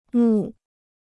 墓 (mù): grave; tomb.